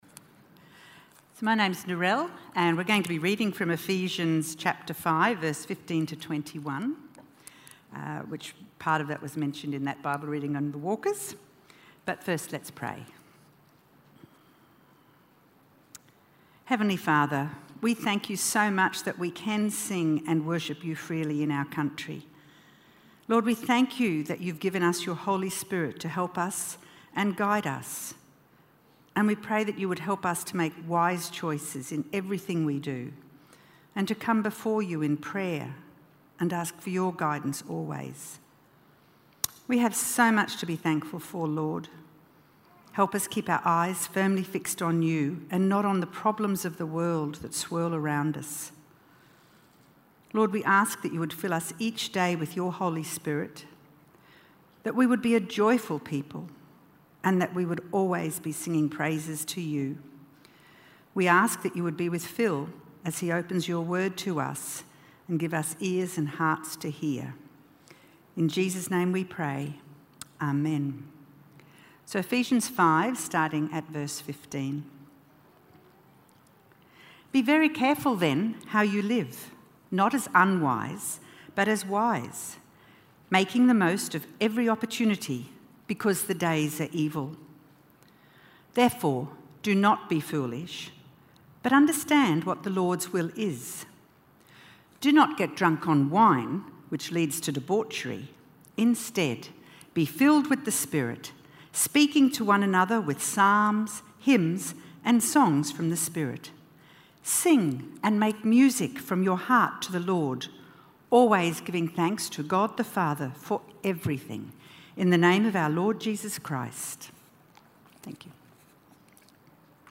FilledWithTheSpirit-BibleTalk.mp3